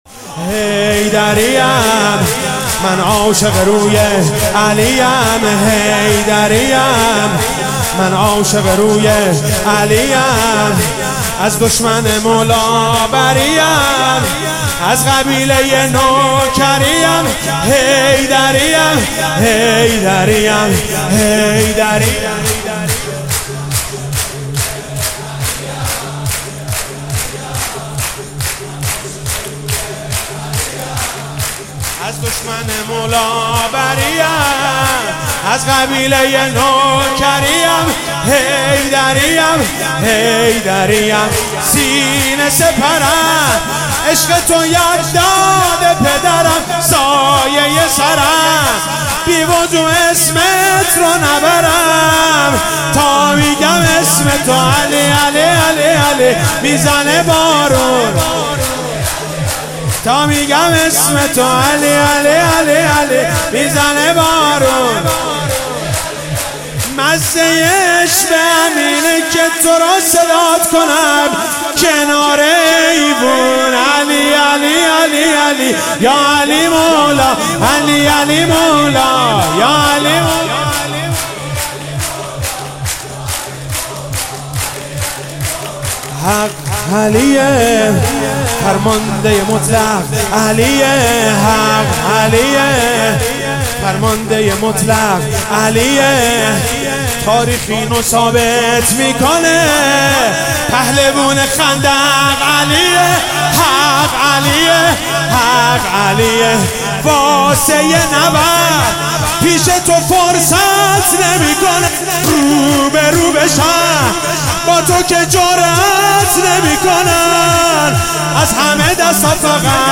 مذهبی
به مناسبت روز پدر – میلاد امام علی علیه السلام